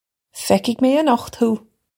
Pronunciation for how to say
Fek-hig may anukht hoo!
This is an approximate phonetic pronunciation of the phrase.